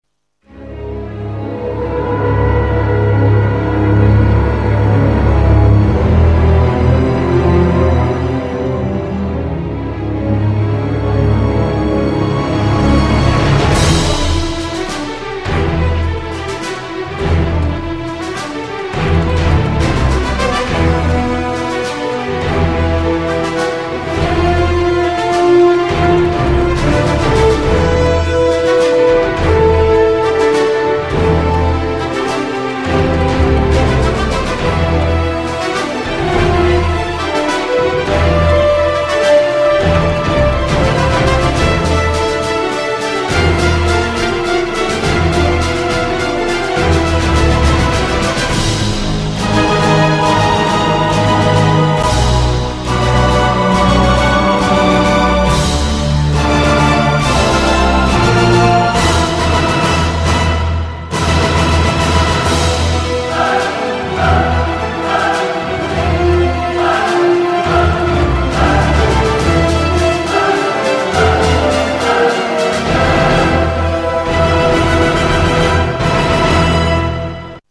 theme music written for the two-part episode.